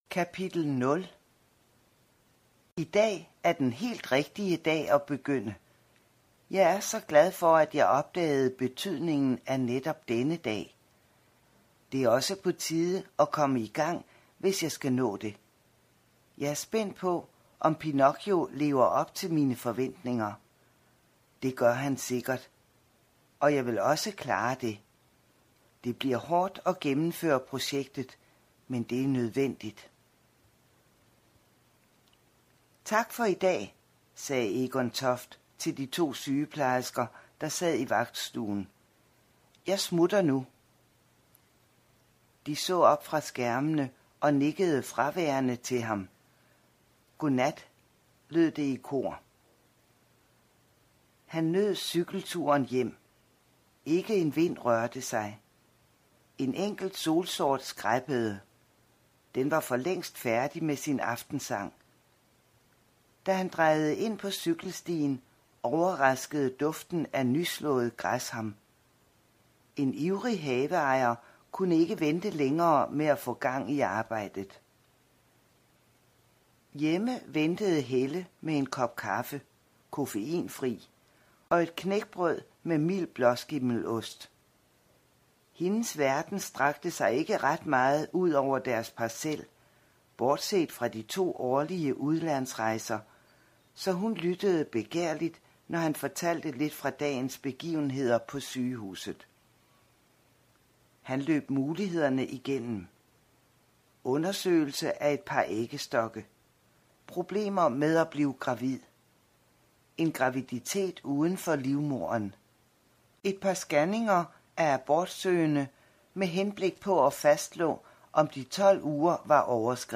Hør et uddrag af Tæl på fingrene Tæl på fingrene Format MP3 Forfatter Jørgen Hedager Nielsen Bog Lydbog E-bog 49,95 kr.